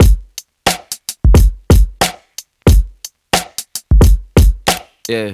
• 90 Bpm Fresh Drum Loop Sample G Key.wav
Free drum loop - kick tuned to the G note. Loudest frequency: 1201Hz
90-bpm-fresh-drum-loop-sample-g-key-3aT.wav